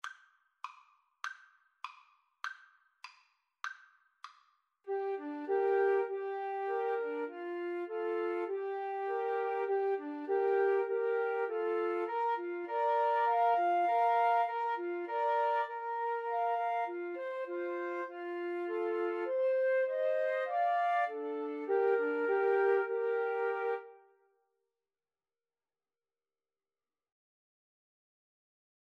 Free Sheet music for Flute Trio
Moderato
Bb major (Sounding Pitch) (View more Bb major Music for Flute Trio )